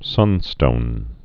(sŭnstōn)